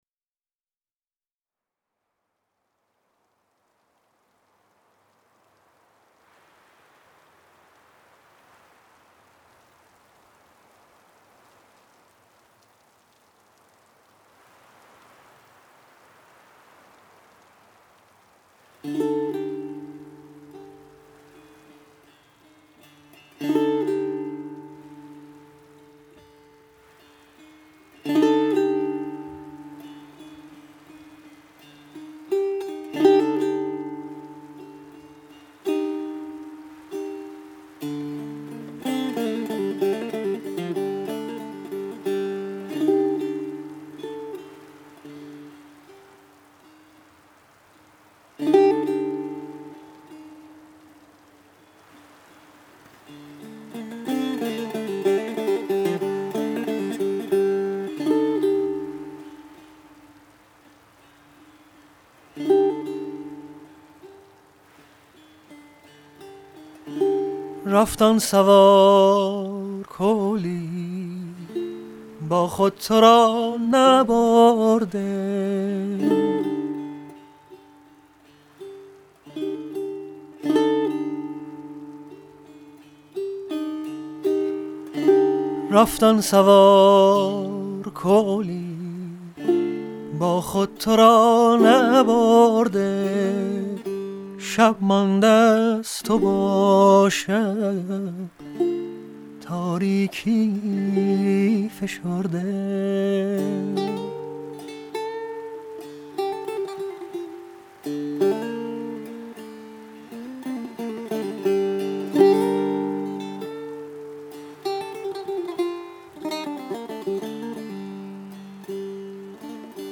آهنگ سنتی